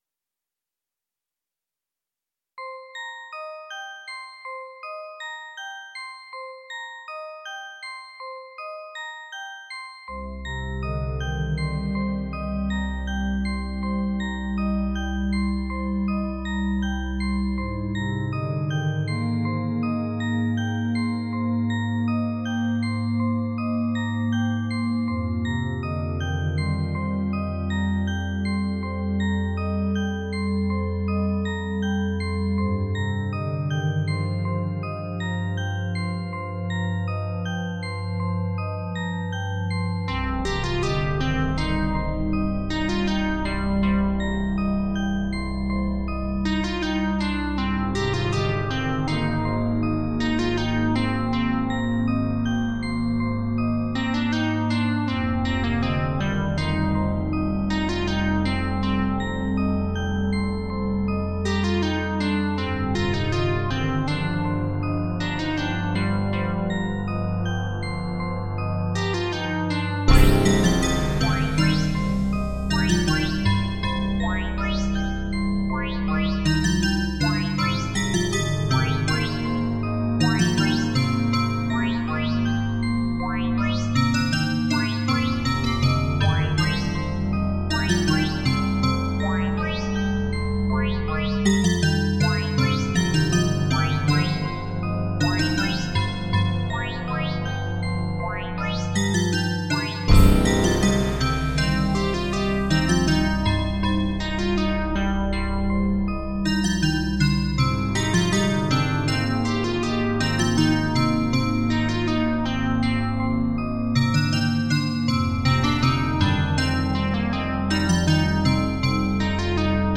It was originally supposed to sound like a horror soundtrack (the Exorcist etc.) but obviously mutated from there.
The drop, so to speak, is sudden with no anticipatory cues. The sounds are too thin and high-pitched.